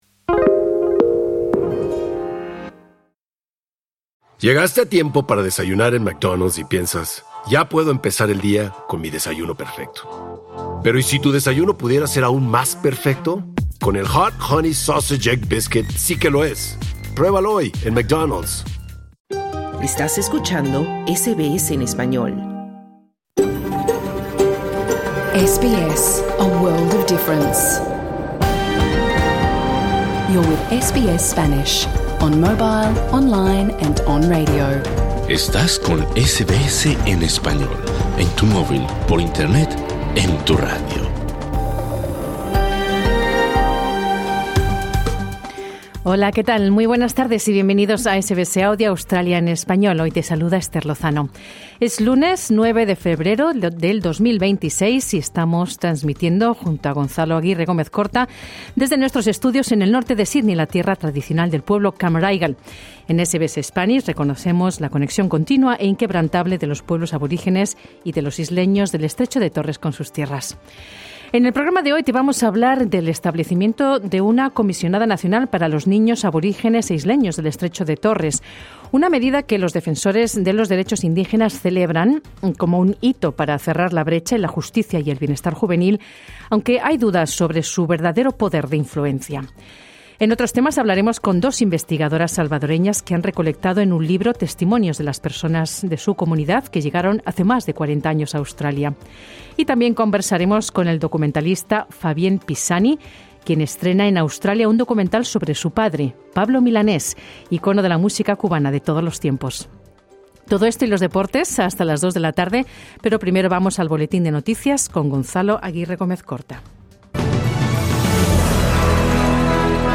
Programa 9/02/26: Conversamos con las autoras del libro que reúne relatos de la dispara salvadoreña en Australia. Además, hablamos del establecimiento de un Comisionado Nacional para los niños aborígenes e isleños del Estrecho de Torres. Y te contamos del documental sobre el cantante cubano Pablo Milanés.